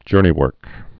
(jûrnē-wûrk)